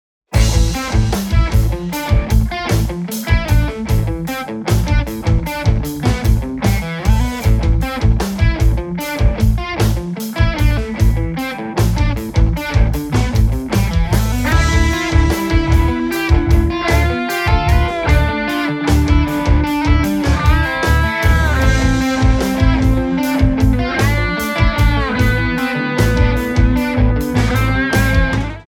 rock instrumental à la guitare
Guitare électrique
Batterie
Basse